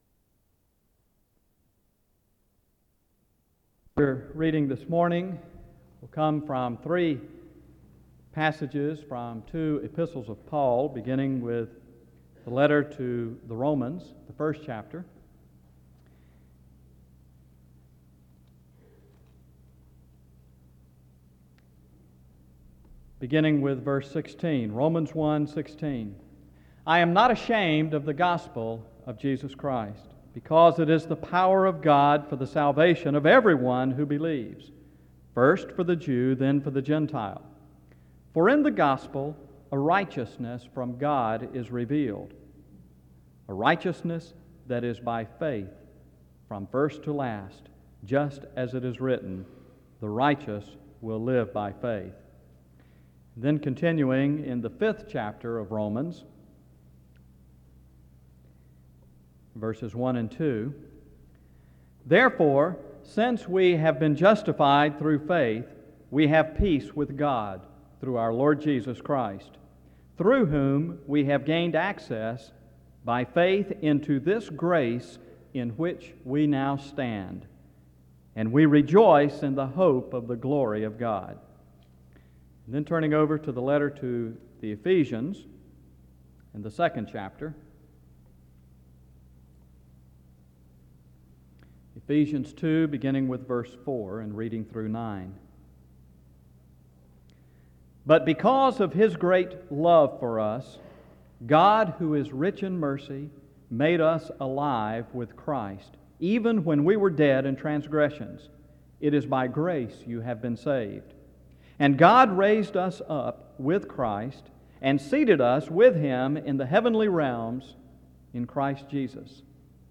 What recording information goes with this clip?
Download .mp3 Description The audio was transferred from audio cassette.